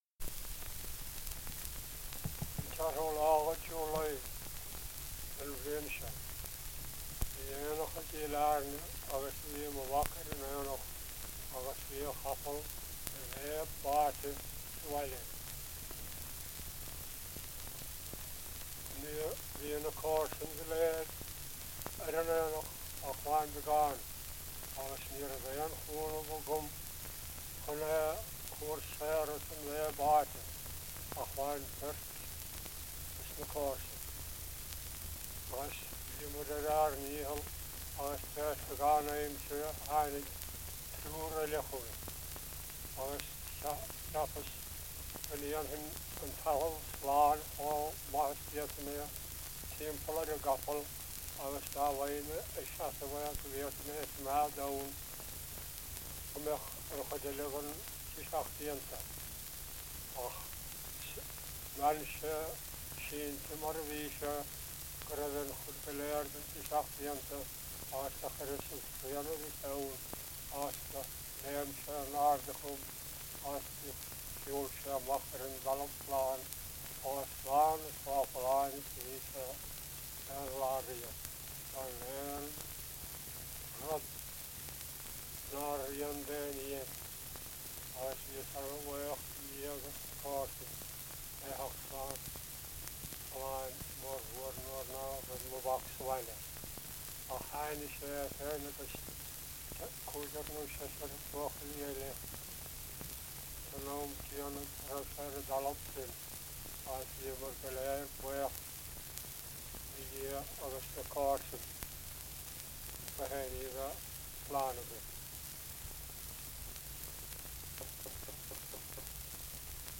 • Anecdotes -- Ireland
• Accents and dialects - Irish language - Ireland: Kerry